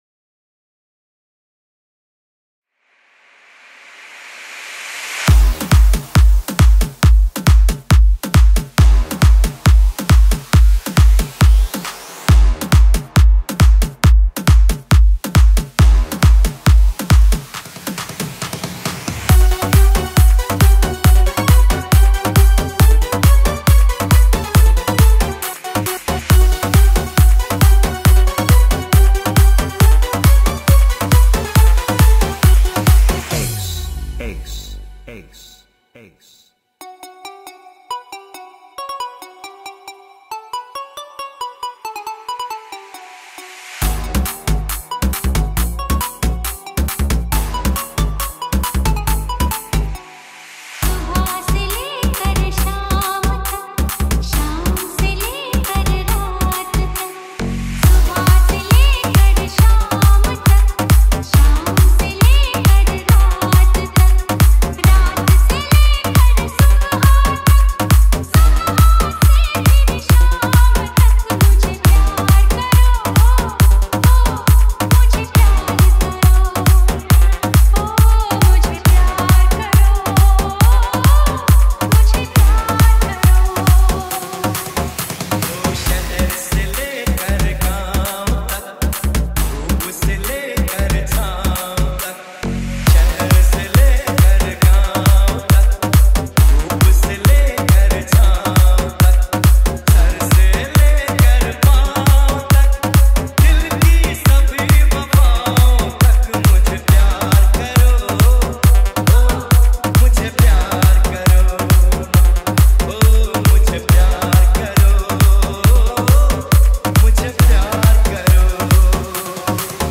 Edm